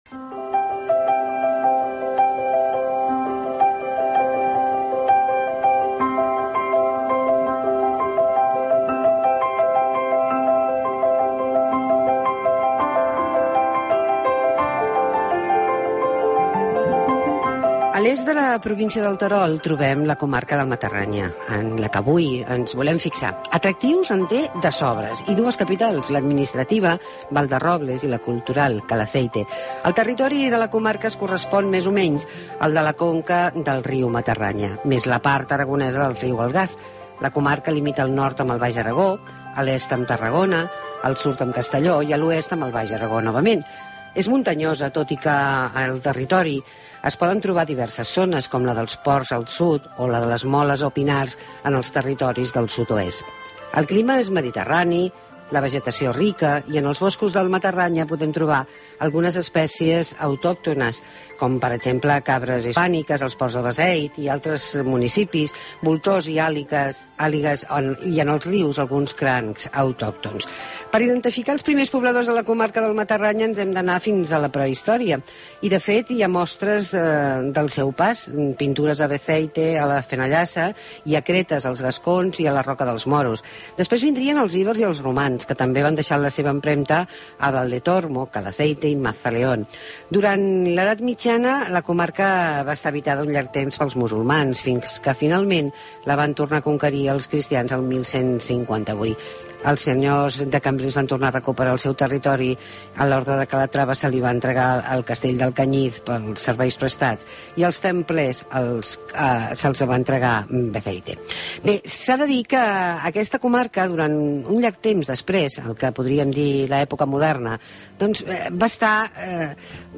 Entrevista a Francisco Esteve,presidente del Consejo Comarcal del Matarraña